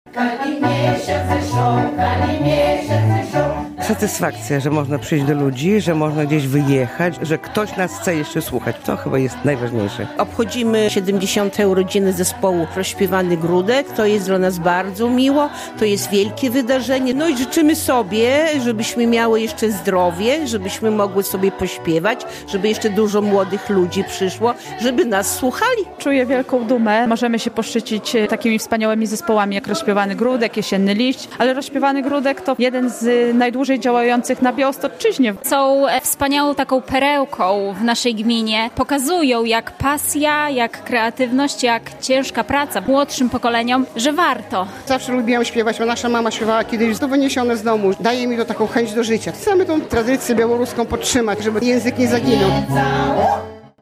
Piękno i siłę ich tradycyjnych głosów znają słuchacze nie tylko w naszym regionie, ale i w całej Polsce. Zespół "Rozśpiewany Gródek” świętował w sobotę (22.02) swój jubileusz, 70-lecie istnienia.